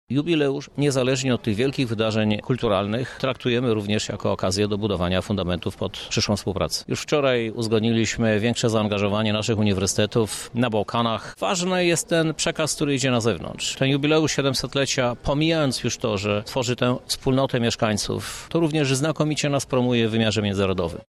Jak mówi prezydent Lublina, Krzysztof Żuk, ten dzień to doskonała okazja do spotkania się z zagranicznymi przyjaciółmi, ale nie tylko: